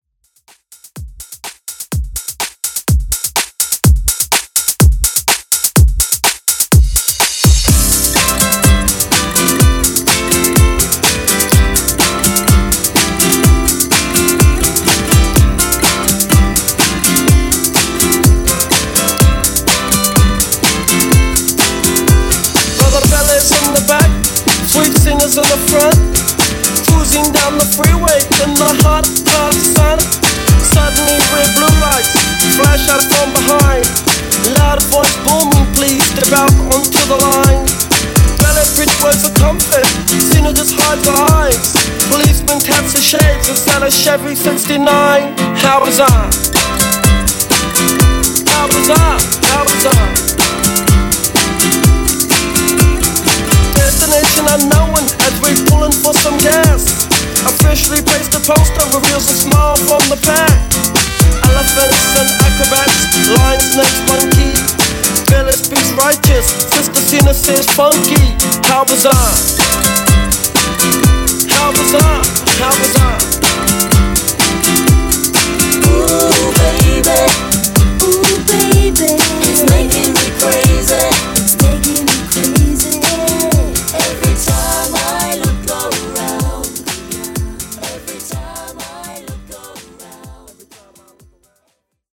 Genre: TOP40
Clean BPM: 125 Time